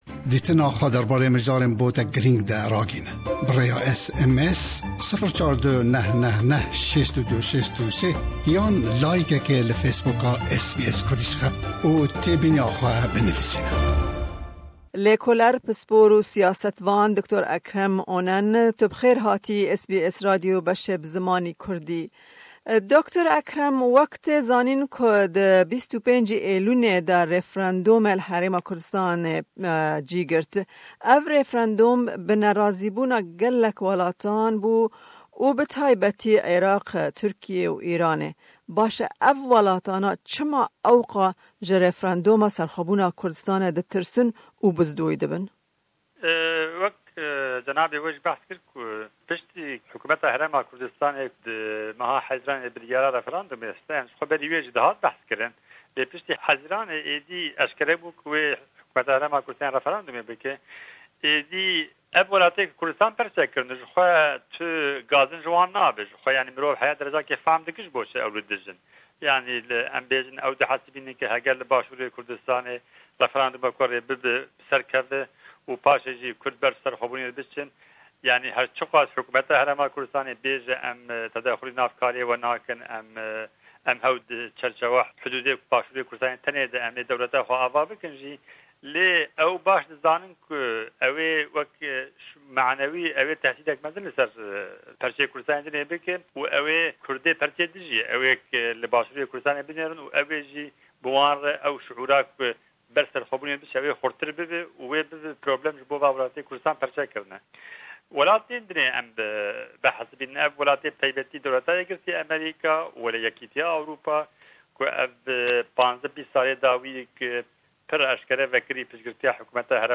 me hevpeyvînek li ser siberoja têkiliyên di navbera Îran, Tirkiye û Îraqê tev Herêma Kurdistanê li dû pêkanîna rêfrendomê pêk anî û em herweha li ser chima ew welatana ji refirenduma serxwebûna Kurdistanê ditirsin axifîn.